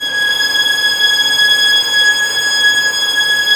Index of /90_sSampleCDs/Roland - String Master Series/STR_Vlns Bow FX/STR_Vls Sul Pont